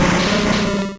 Cri de Smogo dans Pokémon Rouge et Bleu.